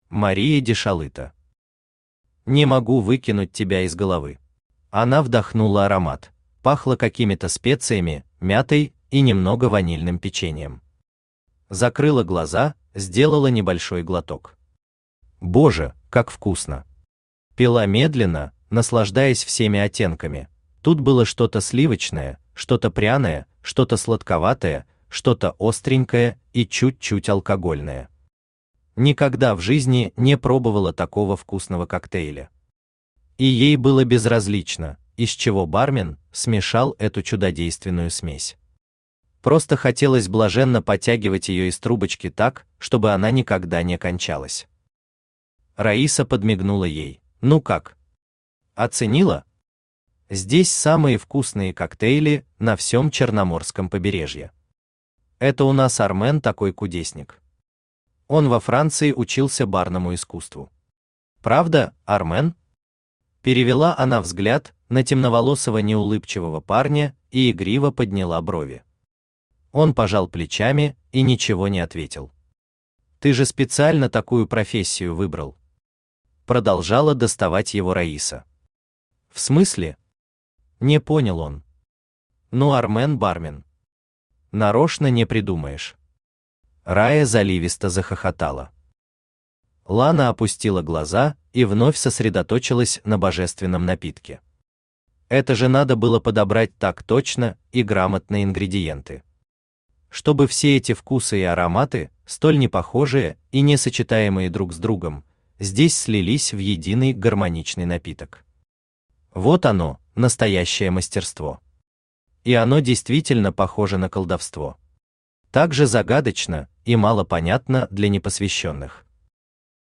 Аудиокнига Не могу выбросить тебя из головы | Библиотека аудиокниг
Aудиокнига Не могу выбросить тебя из головы Автор Мария Дешалыто Читает аудиокнигу Авточтец ЛитРес.